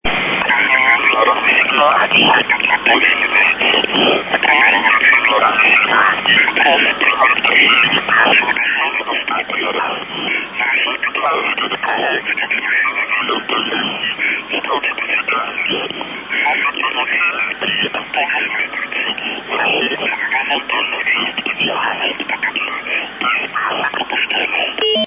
98 Кб 06.06.2008 23:17 Голос через маскиратор.